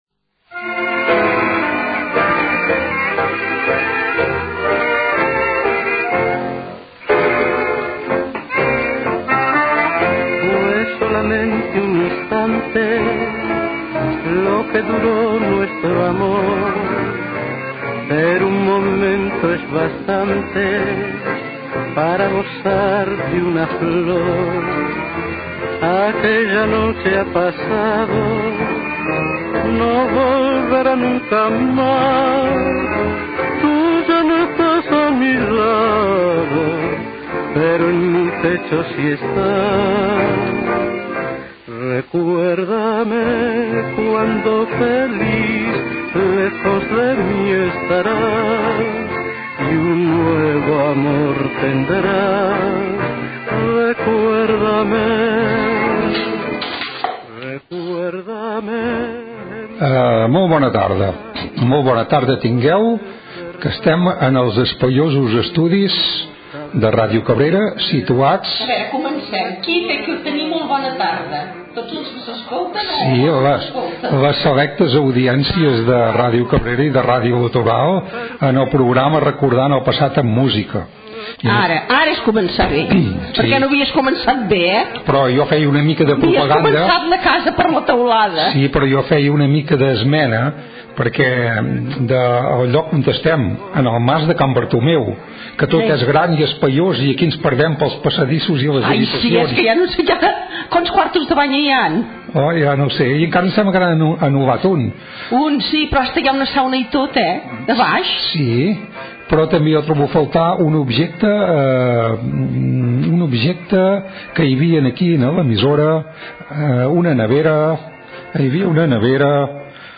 Sintonia, presentació amb la identificació de l'emissora i diàleg dels presentadors sobre els estudi de Ràdio Cabrera i presentació del primer tema musical
Musical